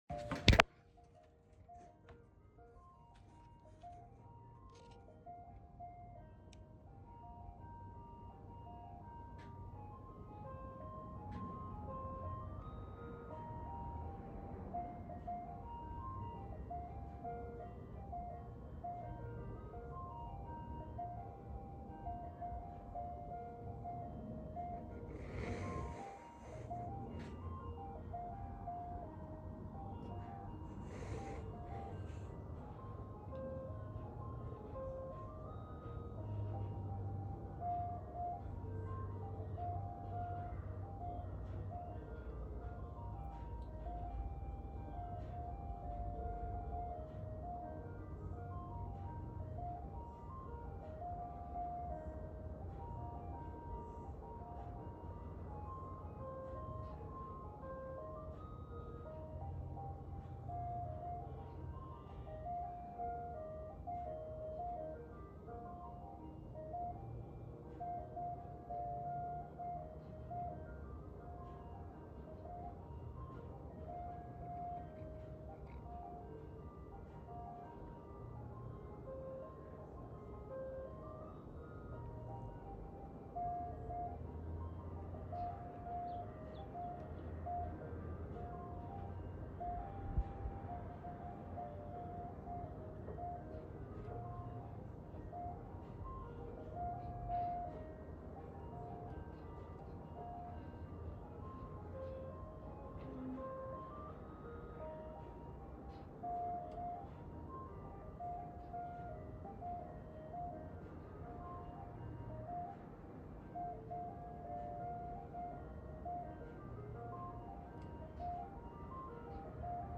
It's not ice cream truck everywhere, but it is here in Aurora, Colorado. This one is singing Turkey In The Straw.